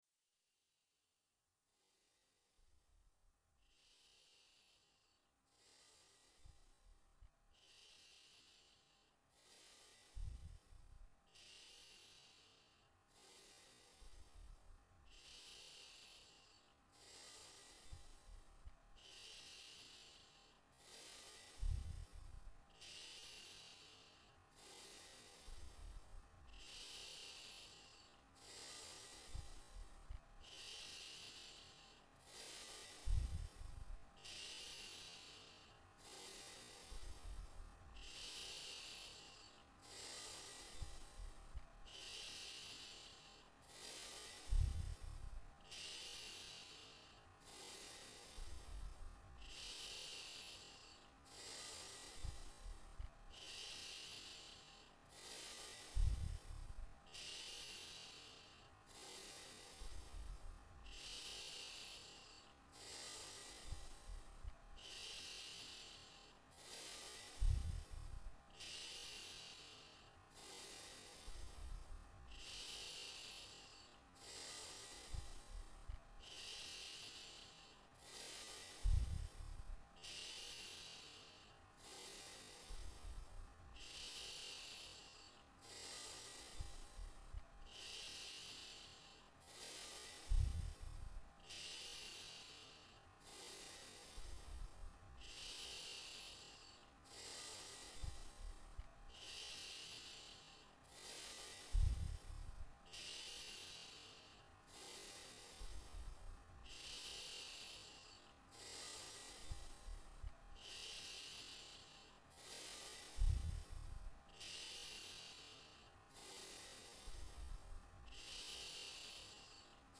الاول اسمه “التنفس المنتظم”يكون هذا التنفس بإيقاع جيد للمبتدئين و الذين يجربون برنامج الحضور للمره الأولى، ويعتبر ايضا ايقاع ممتاز لتطبيقة لمدة 15 دقيقة كاملة، لا يهم كم مره اجرينا فيها عملية التنفس اذا كنا نؤدية بشكل واعي، من المستحب ان تقوم بعملية التنفس ببطئ و عمق
How to breathe - Regular.mp3